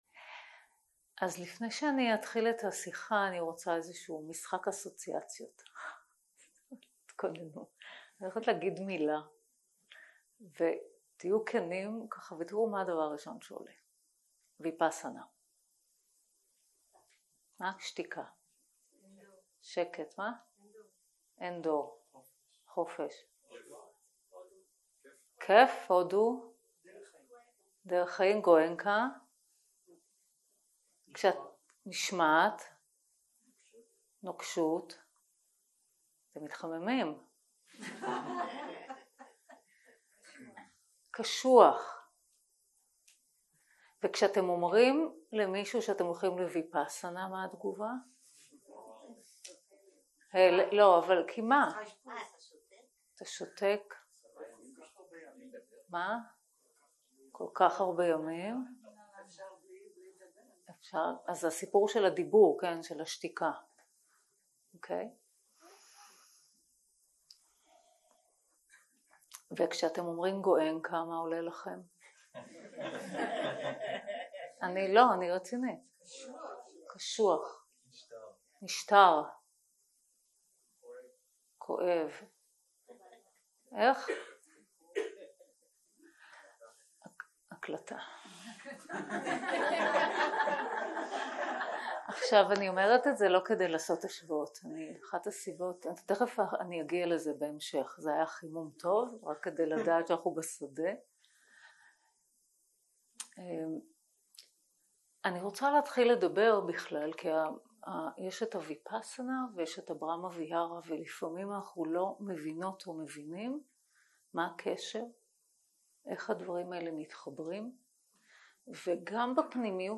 הקלטה 7 - יום 3 - ערב - שיחת דהרמה - המקום של ברהמה ויהרה בתרגול 0:00 0:00 סוג ההקלטה: Dharma type: Dharma Talks שפת ההקלטה: Dharma talk language: Hebrew